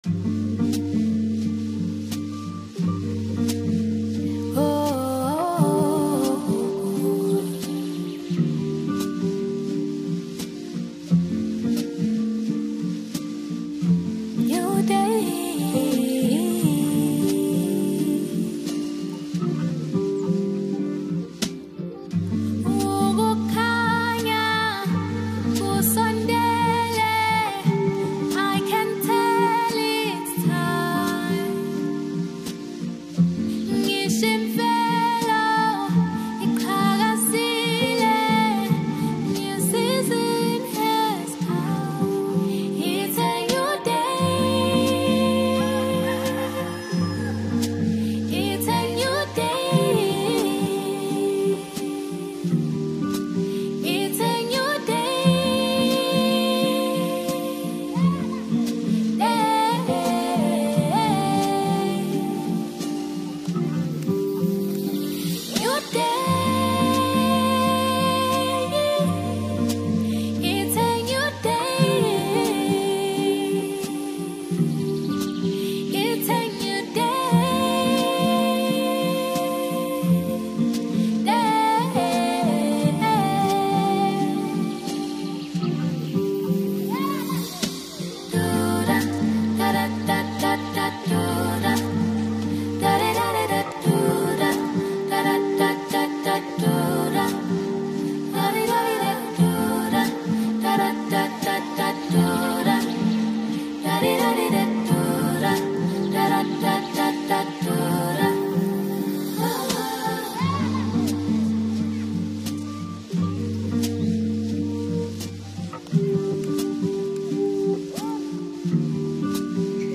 AFRO-POP Apr 07, 2026